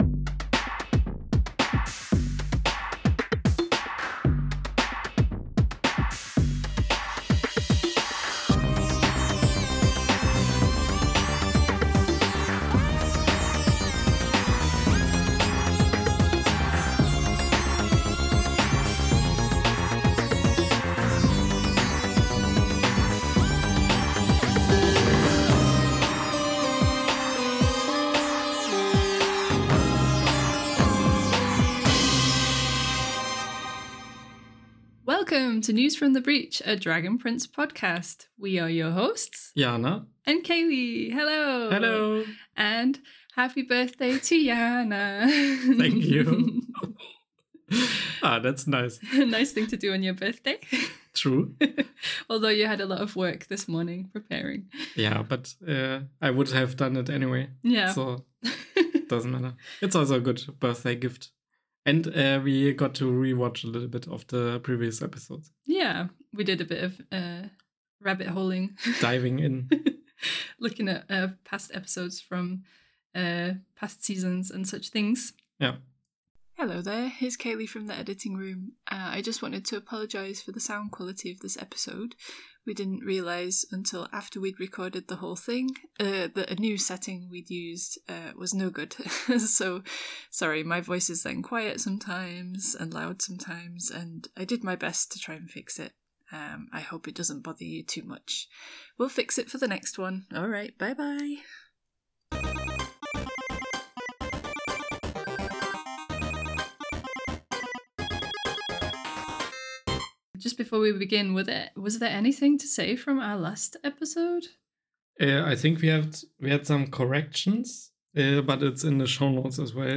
Join us for a fun packed journey, and sorry again for the sound quality this time. There is such a thing as using too much technology.